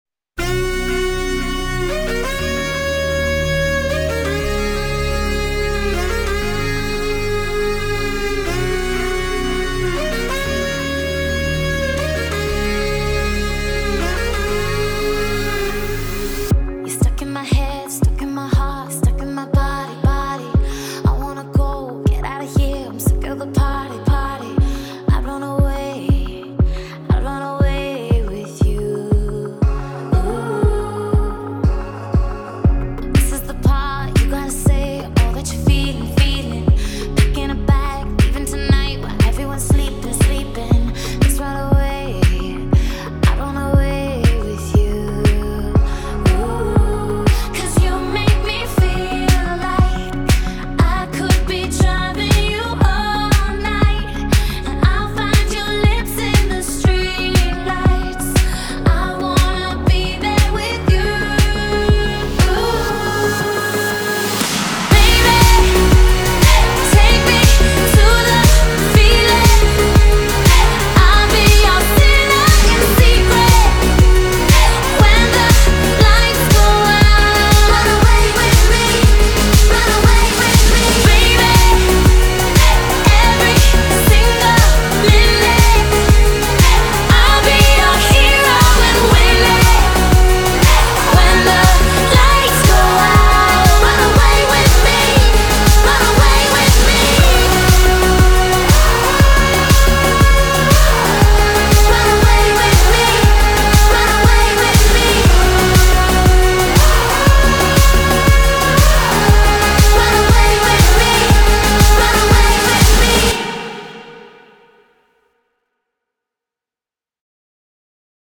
BPM119
MP3 QualityMusic Cut